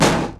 Snares
High For This Snare 2.wav